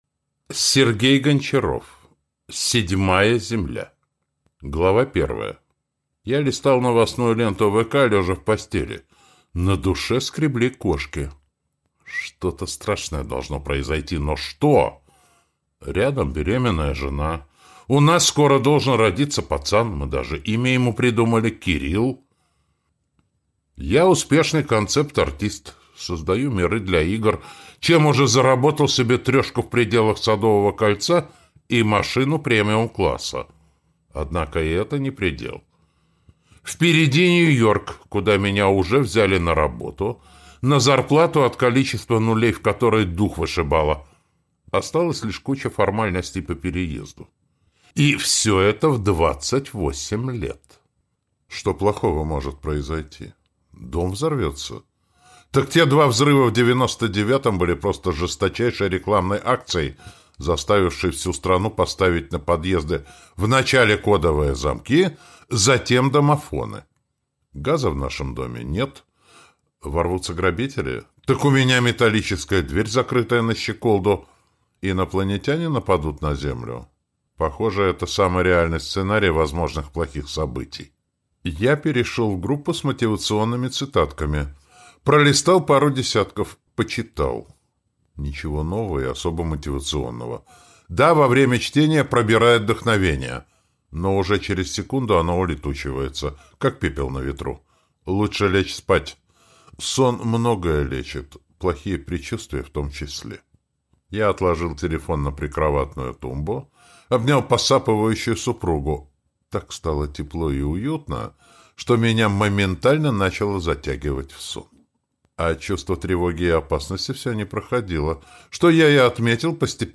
Брат (слушать аудиокнигу бесплатно) - автор Натиг Расулзаде